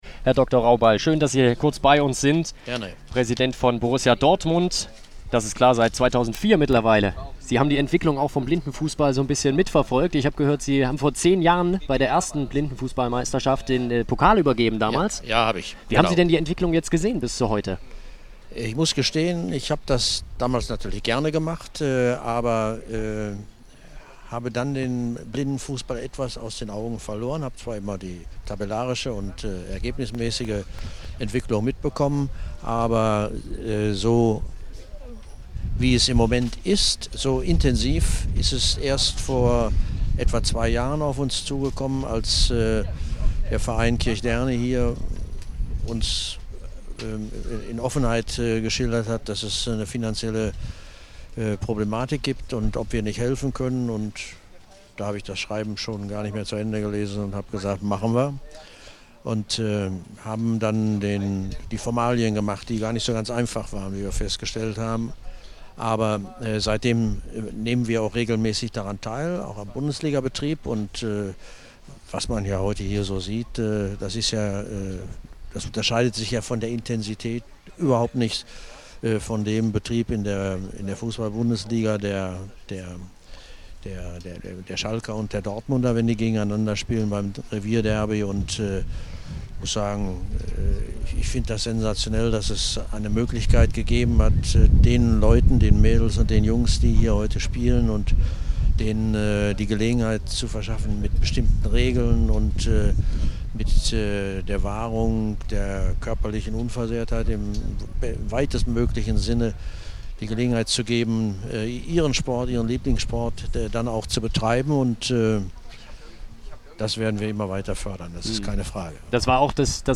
Interview_Rauball_geschnitten.mp3